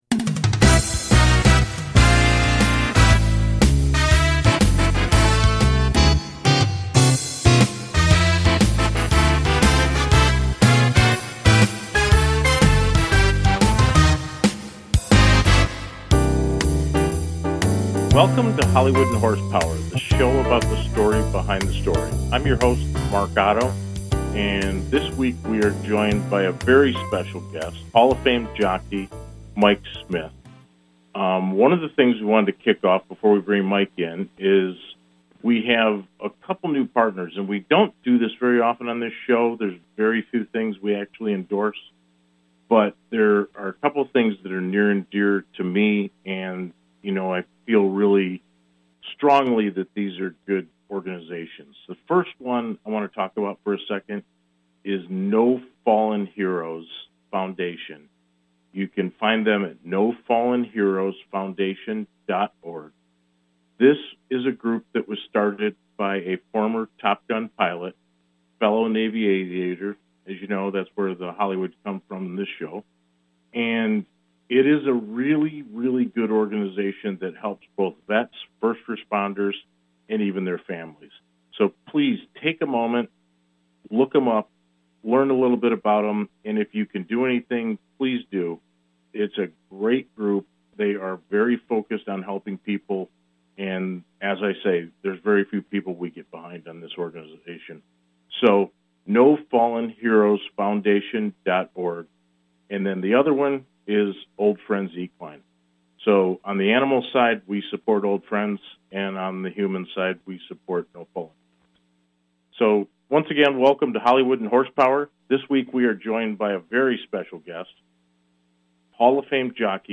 Guest, Mike Smith, Hall of Fame Jockey